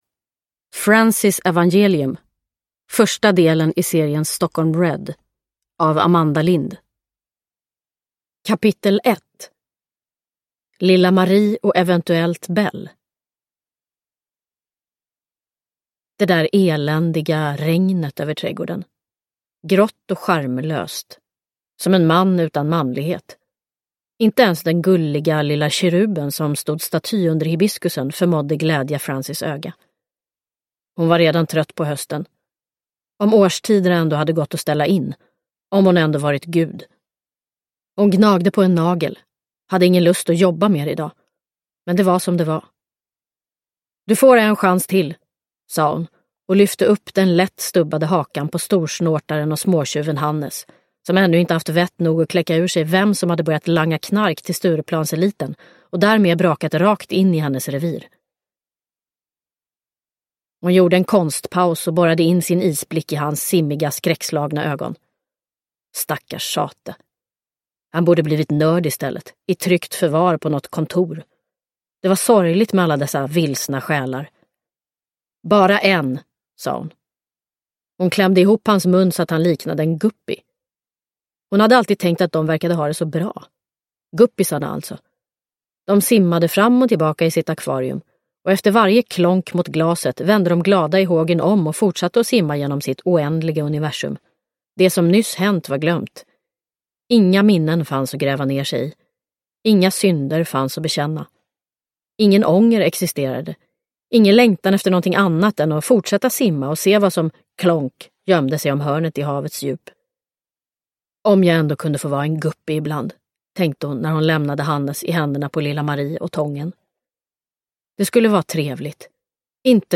Francys evangelium – Ljudbok – Laddas ner
Uppläsare: Rebecka Hemse